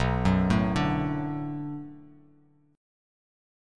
Bm7 Chord
Listen to Bm7 strummed